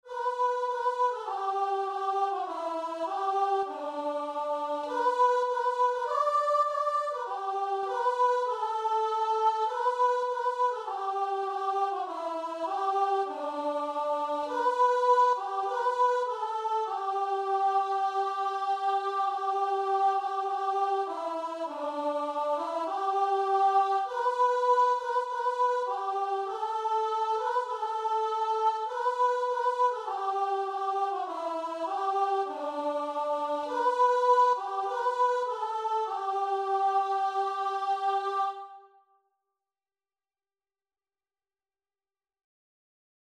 Christian
4/4 (View more 4/4 Music)
Classical (View more Classical Guitar and Vocal Music)